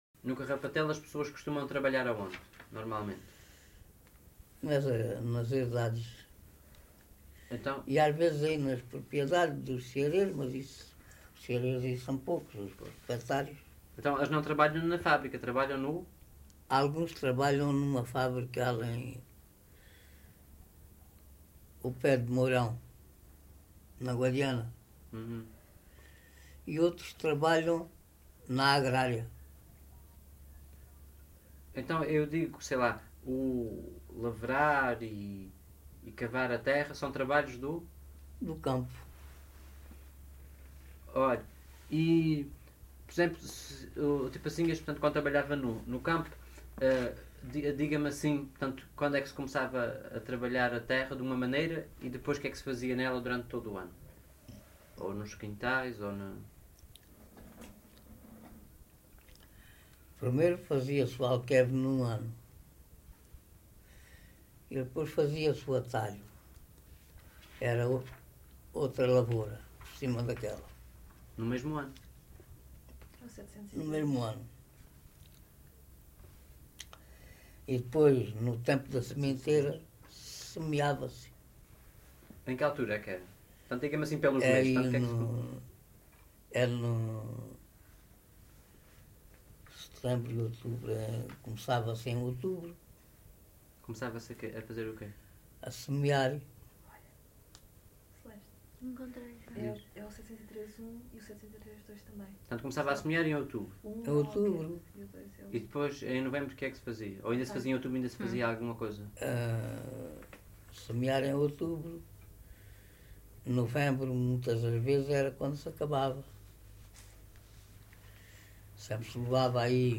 LocalidadeCarrapatelo (Reguengos de Monsaraz, Évora)